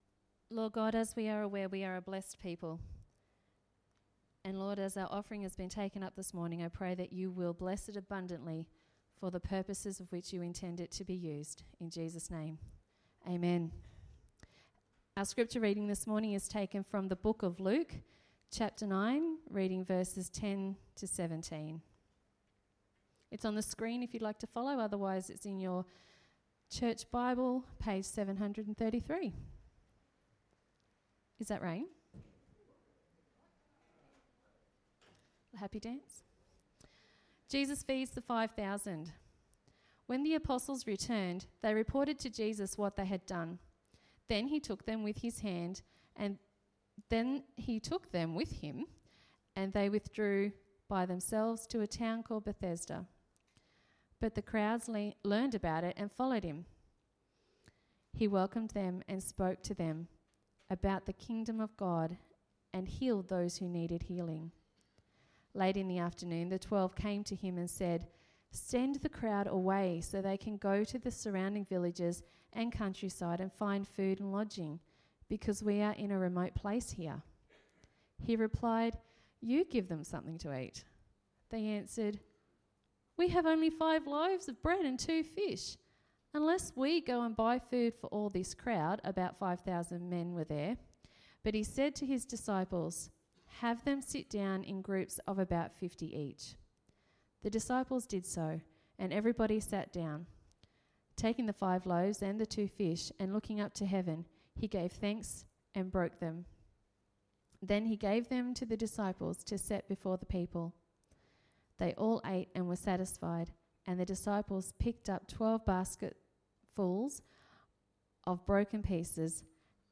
Sermon 26.01.2020